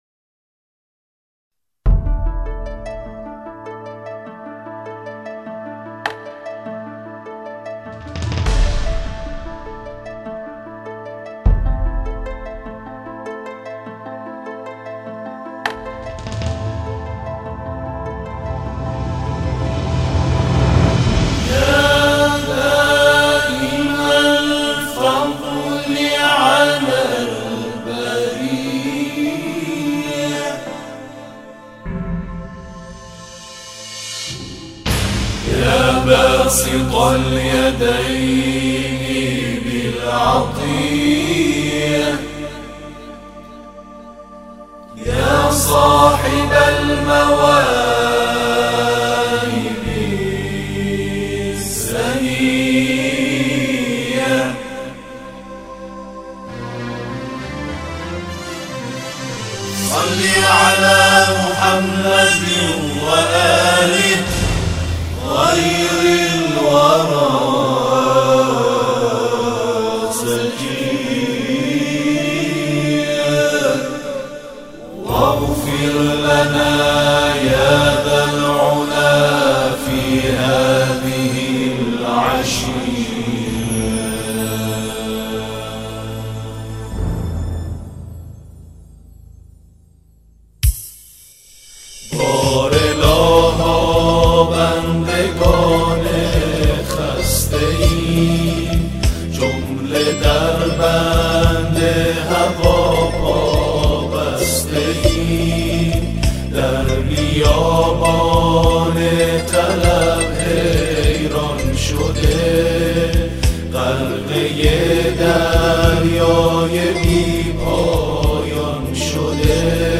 تواشیح و همخوانی